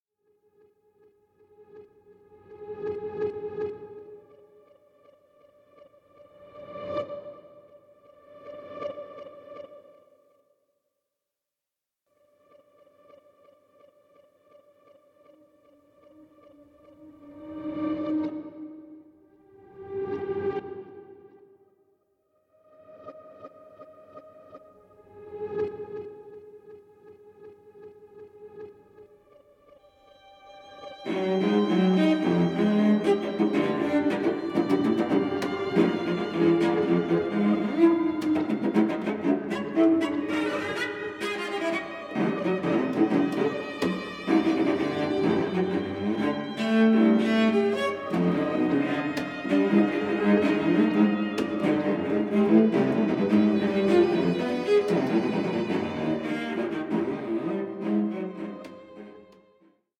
for string quartet and electronics, 2009